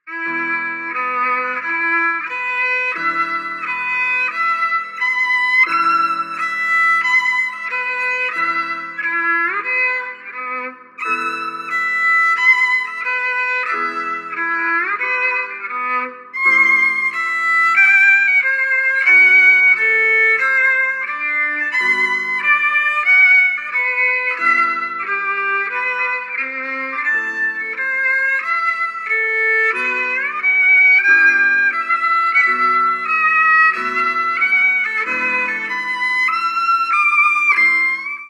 Musical
Al violí